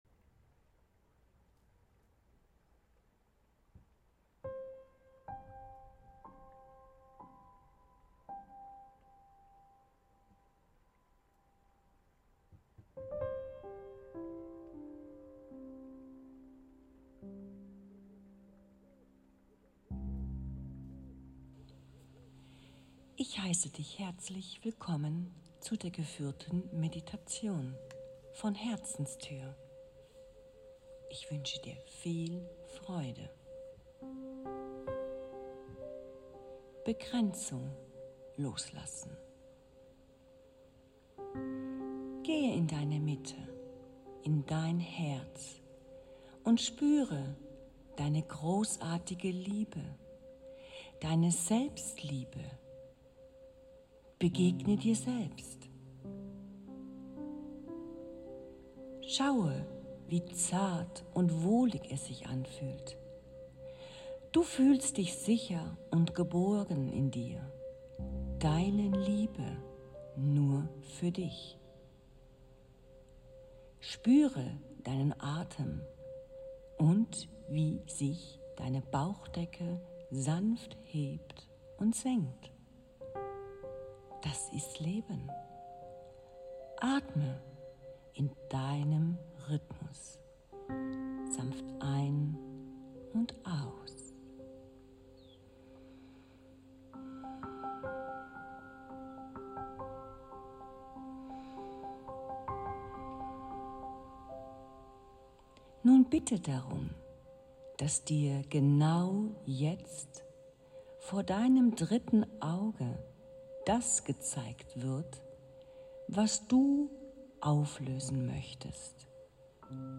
Meditation/Übung Begrenzungen auflösen
Die kraftvolle Musik wurde exklusiv für diese klärende Meditation kreiert, sie trägt eine ganz besondere Schwingung und lehnt sich an meine Worte an.